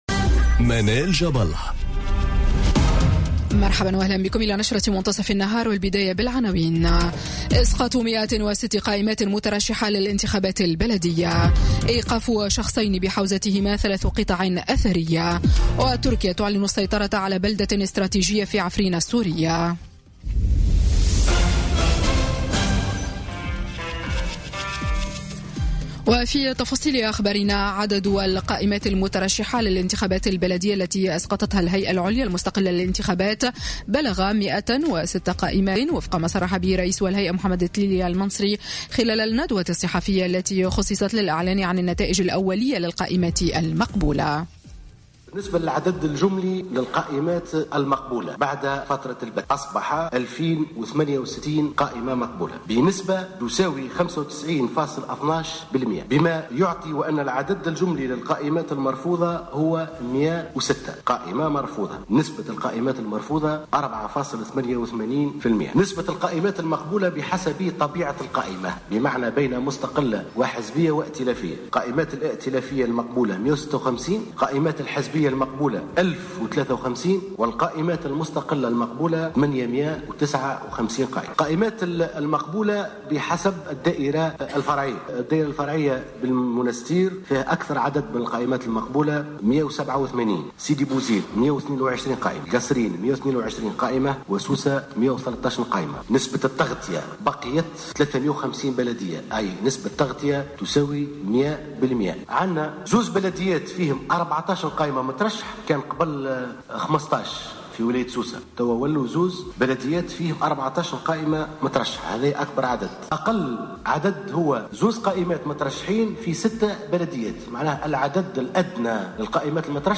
نشرة أخبار منتصف النهار ليوم السبت 3 مارس 2018